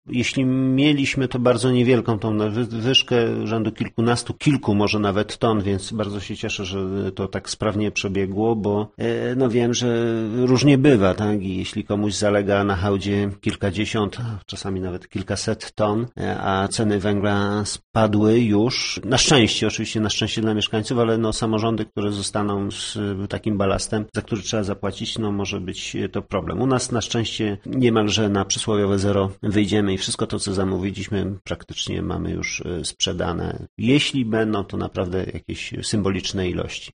Gmina Pajęczno nie ma problemu z zalegającym tańszym węglem, który zamówiła dla mieszkańców. Mówi nam burmistrz Pajęczna Piotr Mielczarek: Jeśli mieliśmy to niewielką nadwyżkę, rzędu kilku-kilkunastu ton.